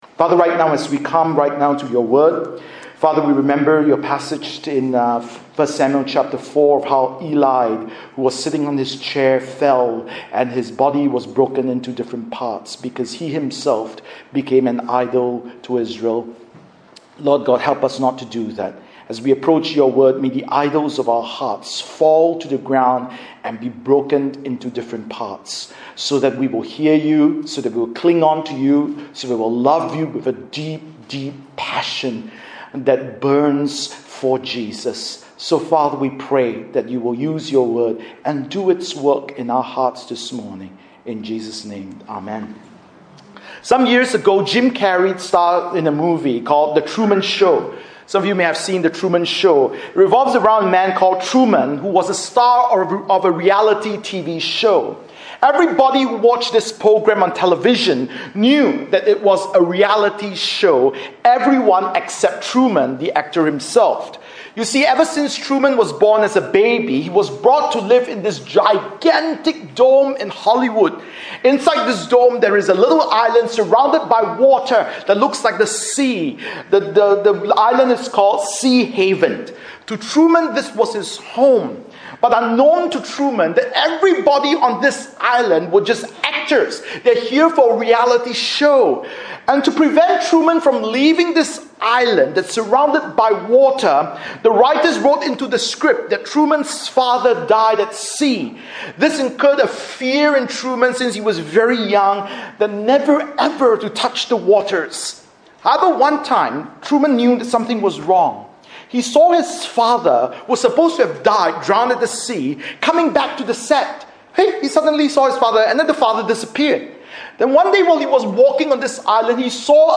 Bible Text: 1 Samuel 3:1-21 | Preacher